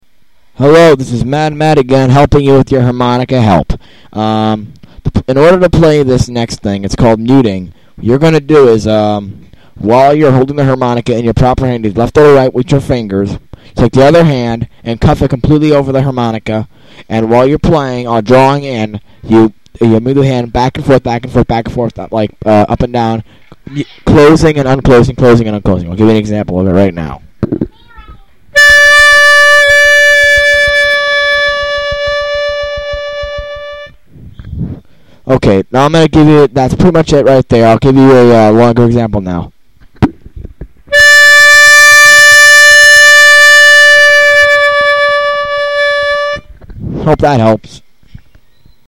Harp Tutorials
Muting: Muting it is great little trick to add to any song.
Demo Clip